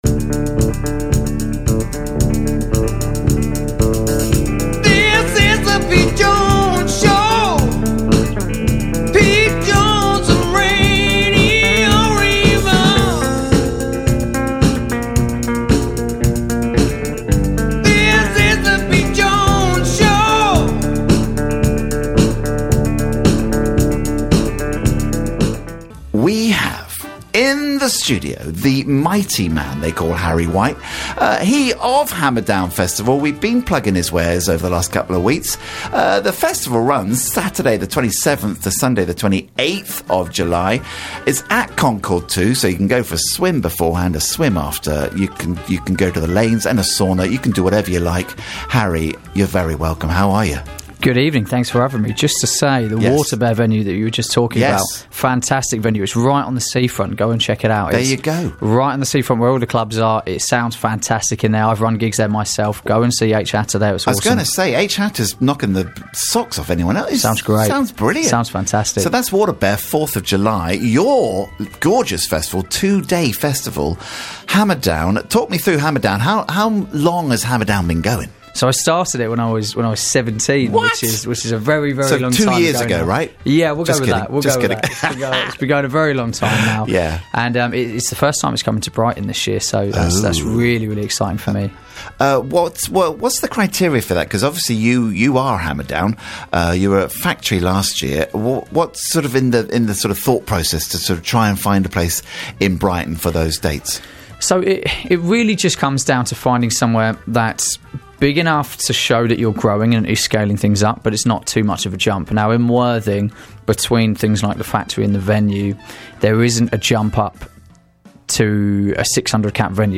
Live chat
Brighton. 3 tracks played from 3 artists performing, including: